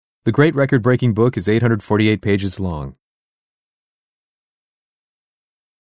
・Tongue Twister（早口言葉）の音声サンプル
・ナレーター：アメリカ英語のネイティブ２名（男女）